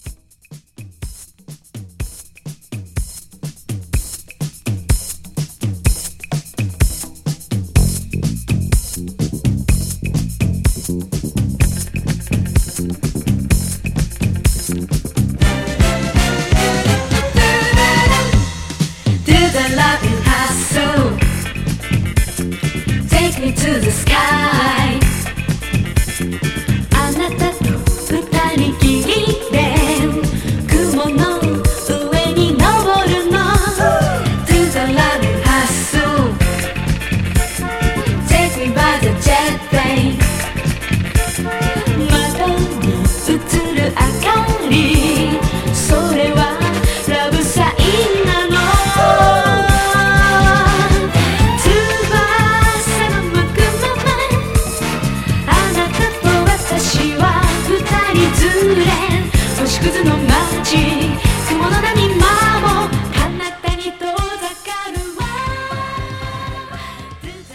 国産ハッスル・ディスコ♪